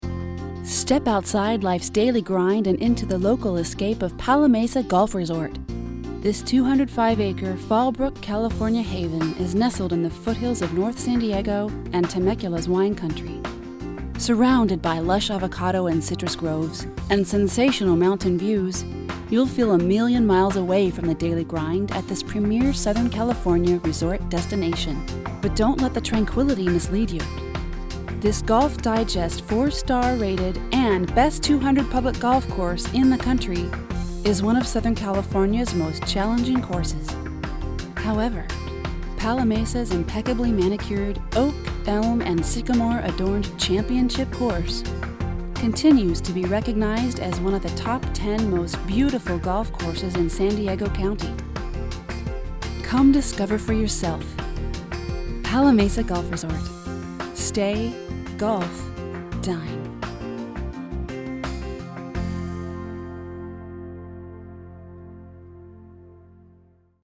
Below are some samples of our voice over work.